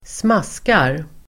Ladda ner uttalet
smaska verb, champ, slurpGrammatikkommentar: A &Uttal: [²sm'as:kar] Böjningar: smaskade, smaskat, smaska, smaskarSynonymer: mumsa, smackaDefinition: låta kletigt och blött (när man äter)